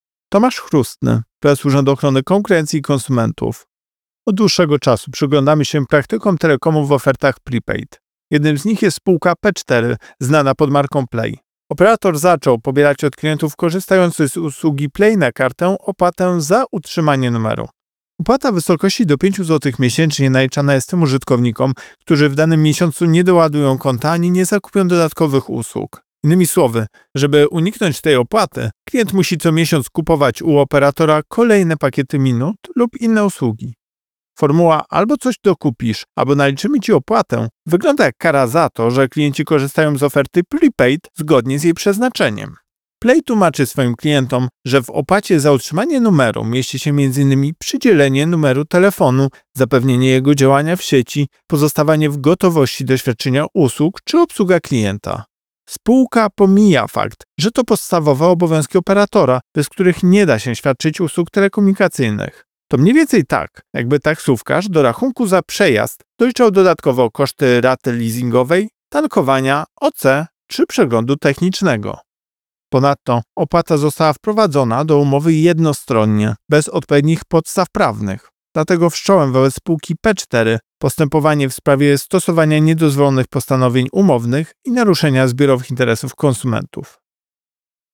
Wypowiedź Prezesa UOKiK Tomasza Chróstnego